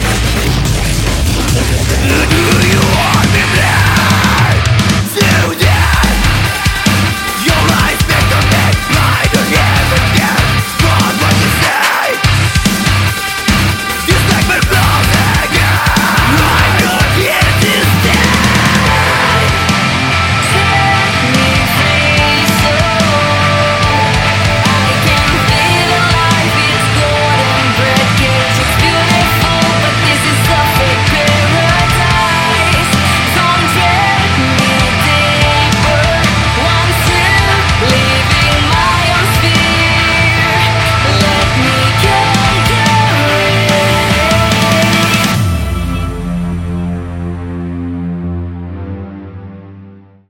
band virtual elektronik